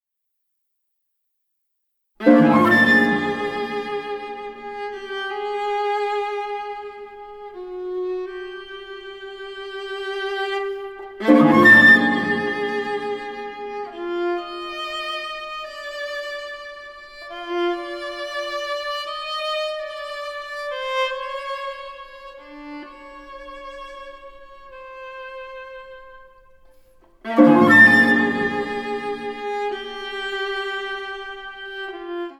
Harp
Flute
Viola Released